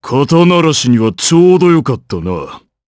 Bernheim-Vox_Victory_jp_b.wav